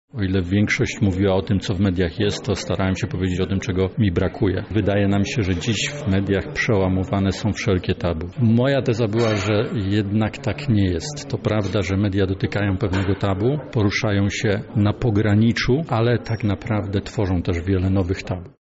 To tytuł ogólnopolskiej konferencji naukowej przygotowanej przez pracowników Katolickiego Uniwersytetu Lubelskiego. Jednym z problemów poruszanych podczas spotkania był temat tabu.